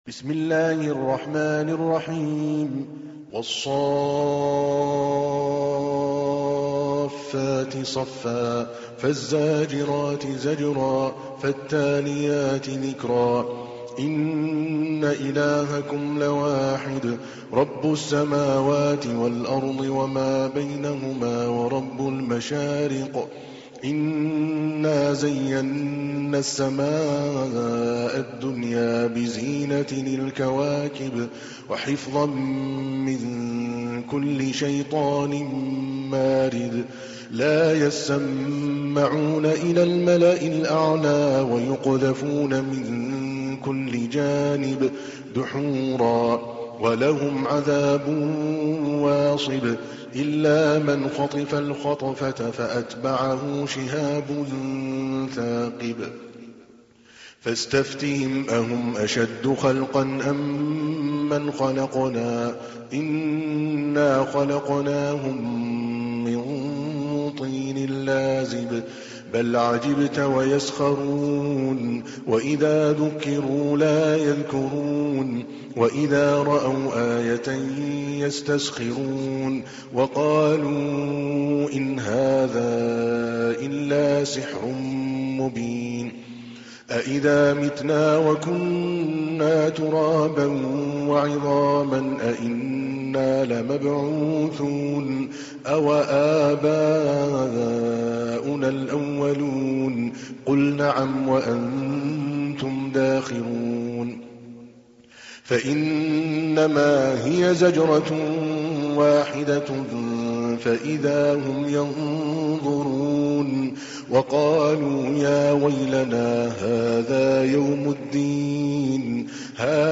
تحميل : 37. سورة الصافات / القارئ عادل الكلباني / القرآن الكريم / موقع يا حسين